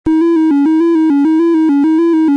These were all recorded as WAV's and converted to MP3's to save space.